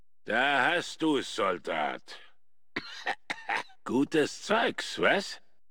Audiodialoge
FOBOS-Dialog-Armpit-007.ogg